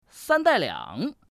Index of /qixiGame/test/guanDan/goldGame_bak/assets/res/zhuandan/sound/woman/